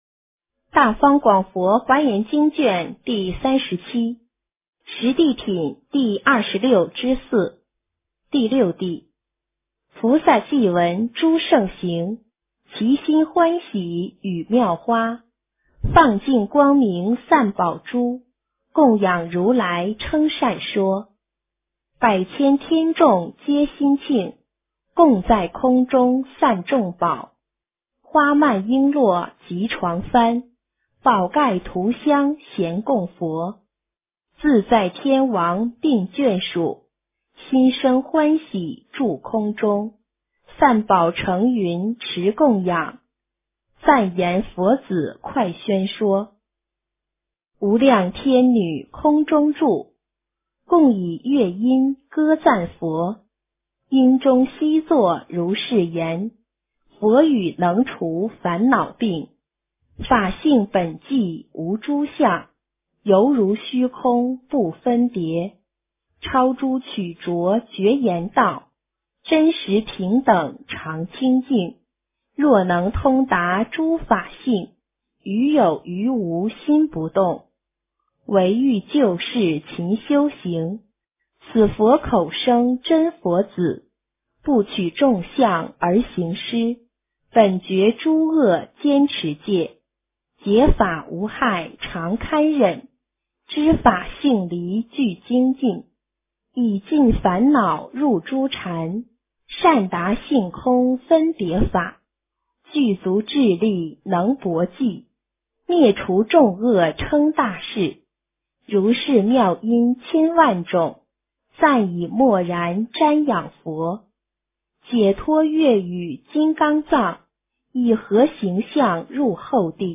华严经37 - 诵经 - 云佛论坛